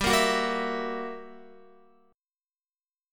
GmM13 Chord
Listen to GmM13 strummed